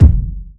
Kick20.wav